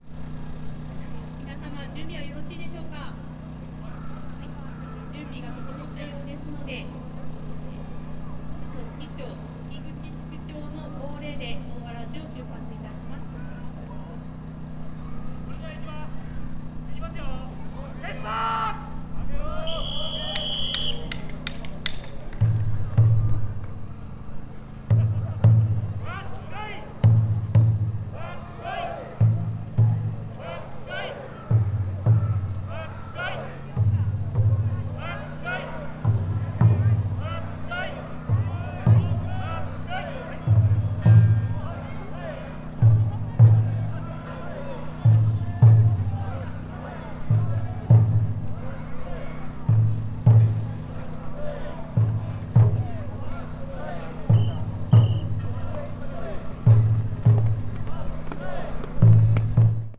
Fukushima Soundscape: Mt. Shinobu